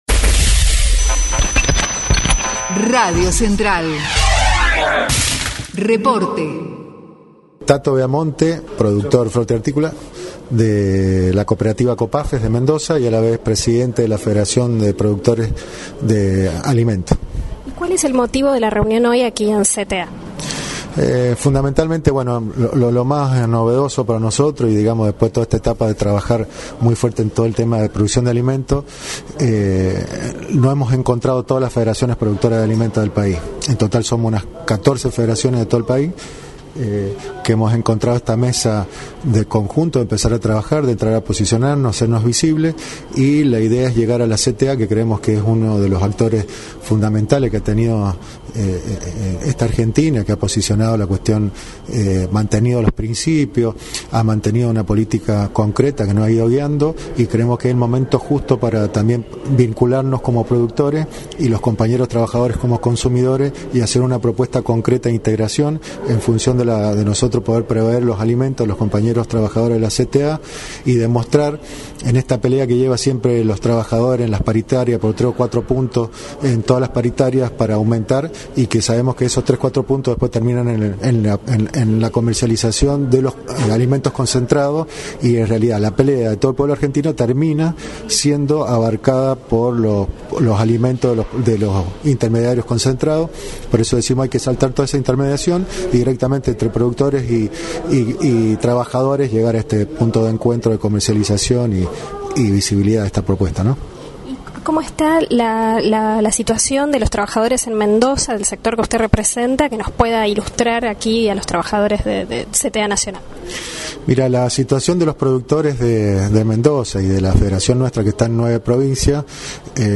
encuentro CTA / Federaciones de Cooperativas Productoras de Alimentos